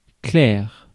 Ääntäminen
France (Paris): IPA: [klɛʁ]